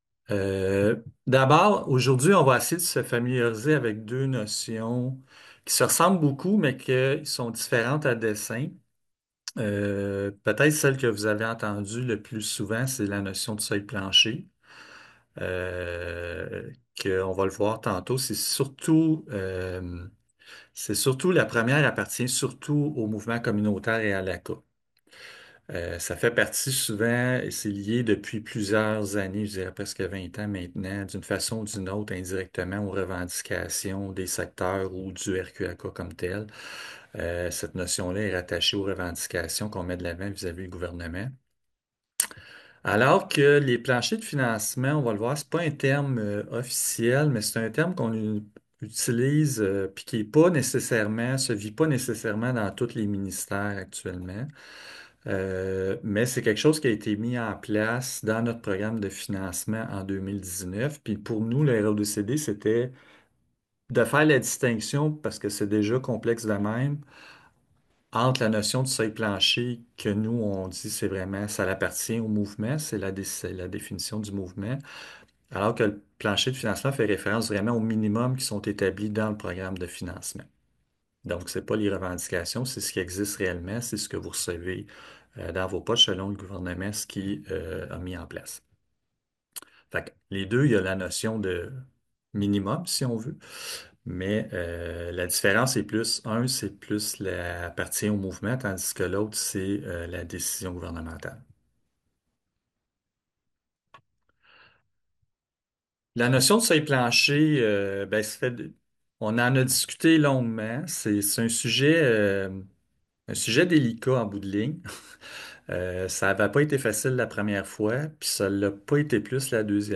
Audio_presentation-paliers-de-financement-RODCD.mp3